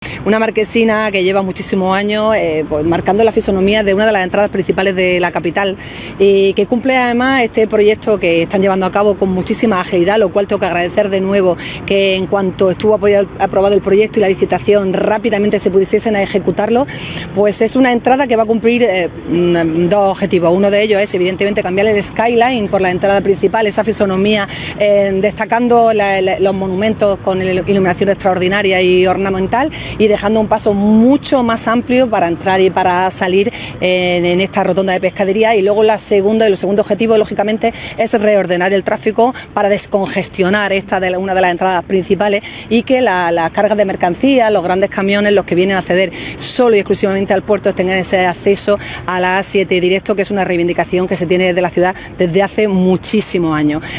ALCALDESA-DERRIBO-MARQUESINA-ENTRADA-PUERTO.wav